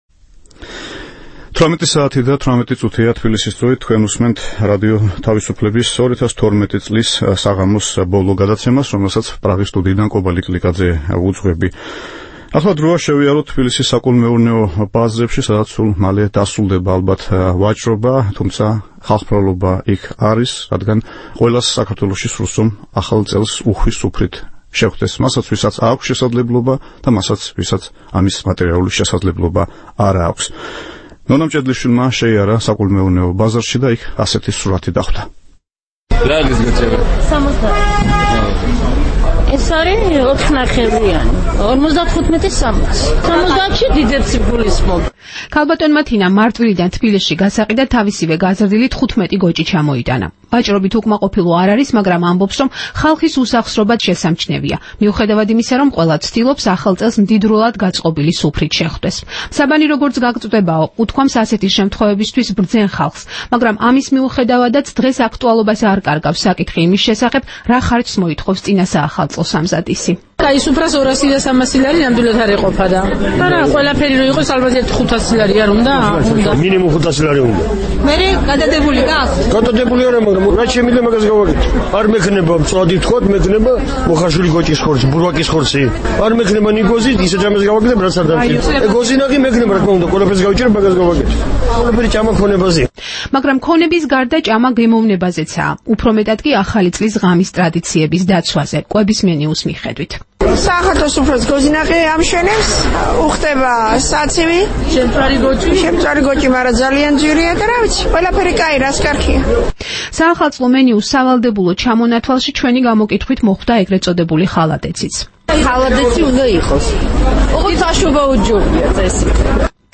რეპორტაჟი თბილისის ბაზრიდან